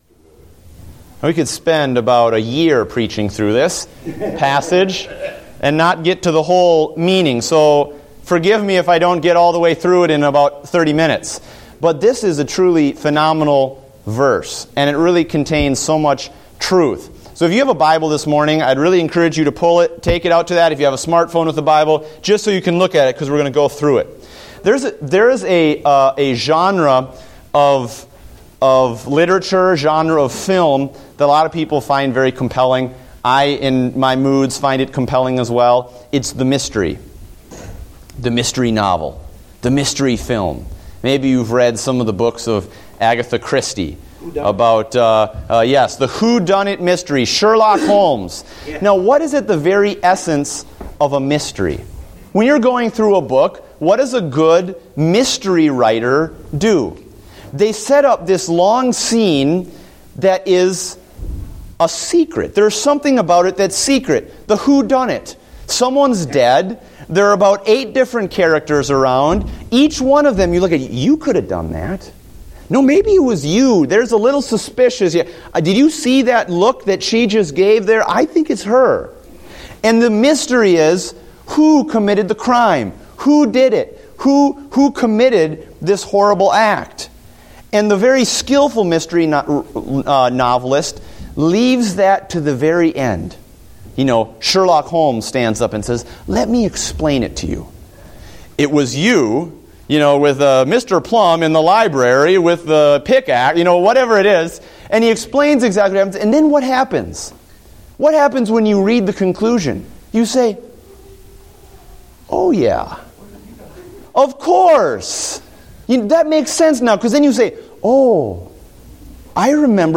Date: December 21, 2014 (Adult Sunday School)